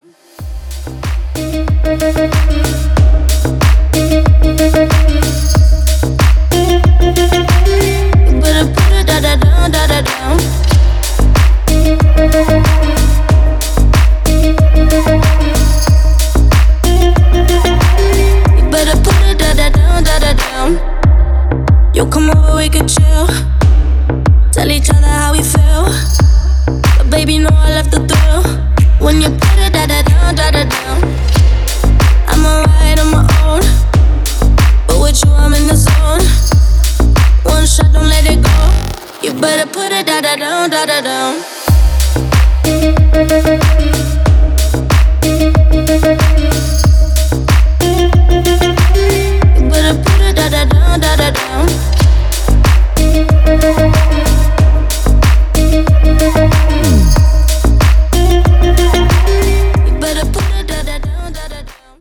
Electronic
EDM
басы
Стиль: deep house